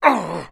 Sound / sound / monster / gupae / damage_4.wav
damage_4.wav